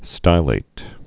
(stīlāt)